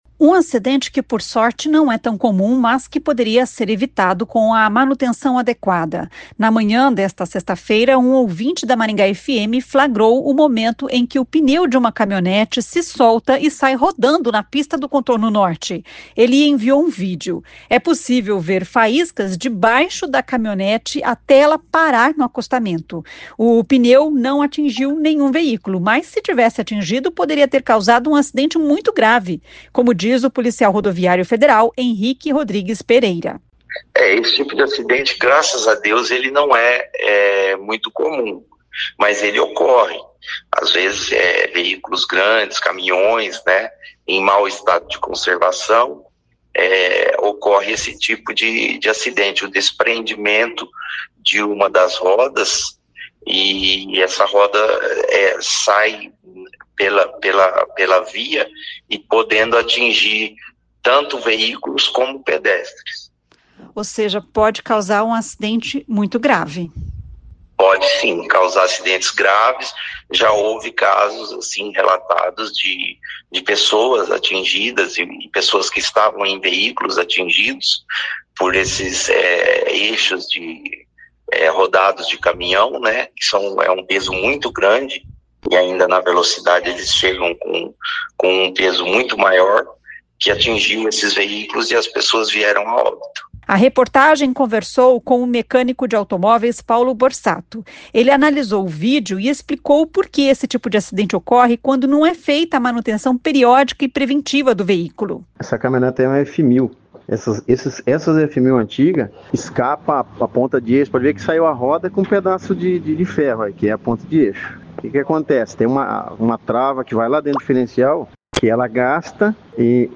Um mecânico ouvido pela reportagem explica por que isso acontece.